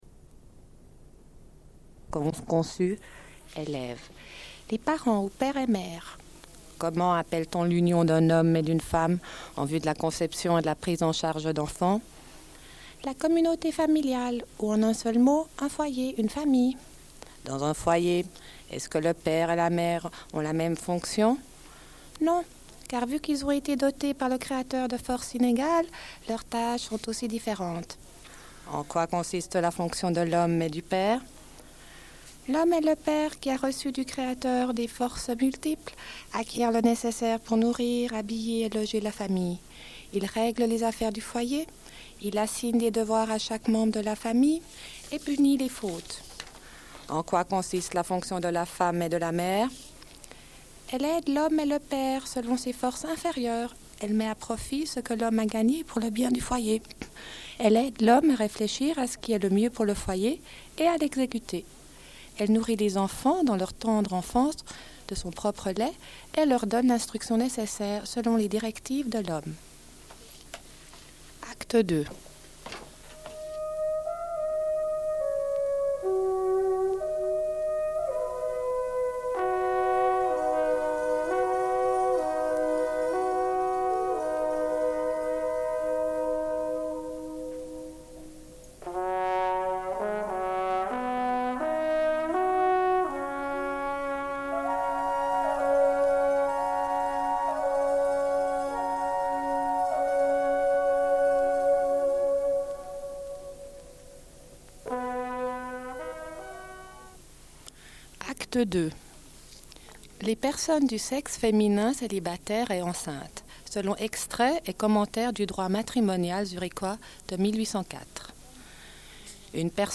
Une cassette audio, face A17:45